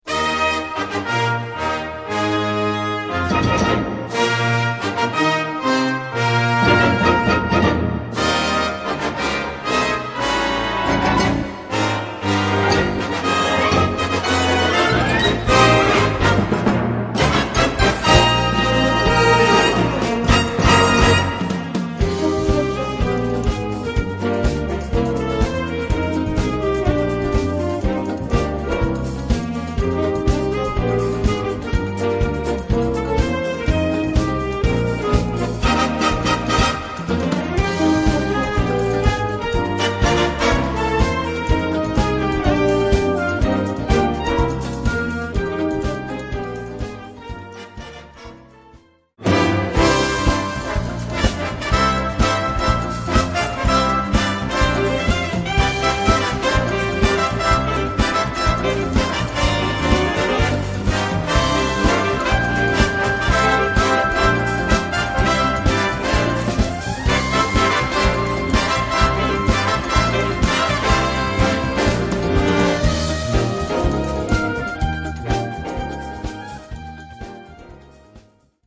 Gattung: Samba
Besetzung: Blasorchester